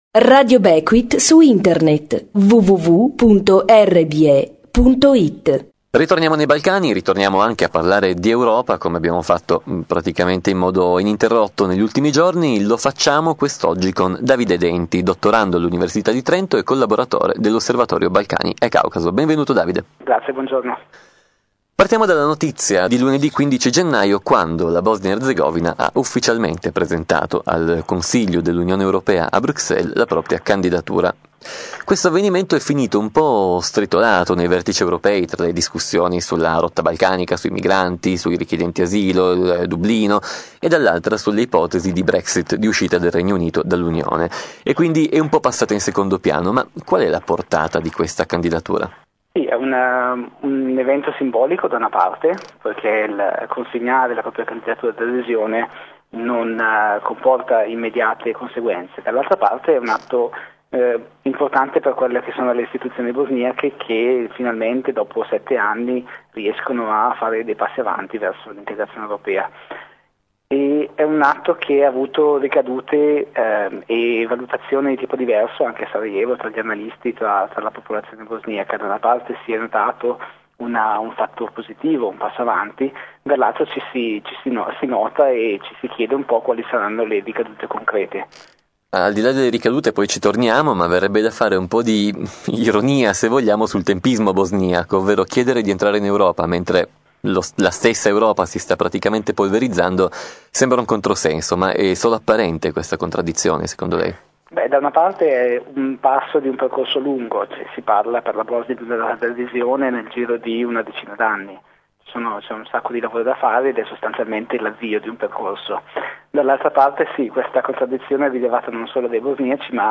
ai microfoni di Radio Beckwith